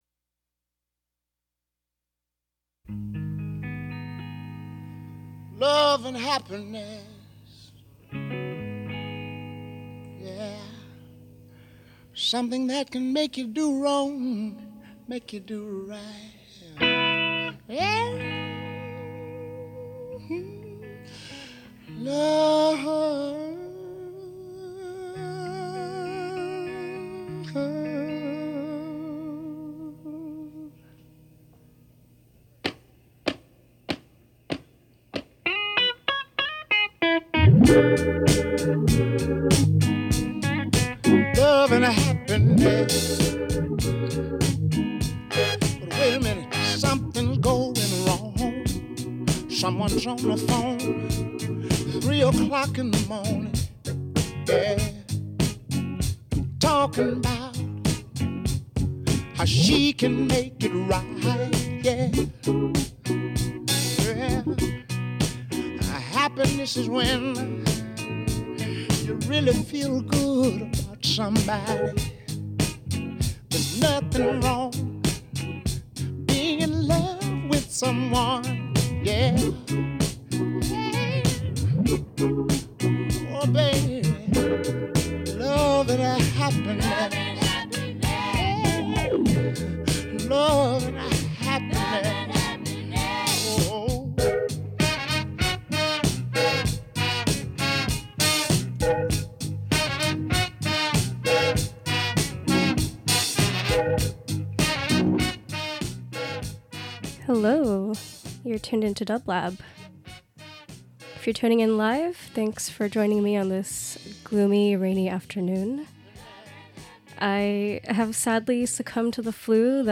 Dance Hip Hop R&B Soundtracks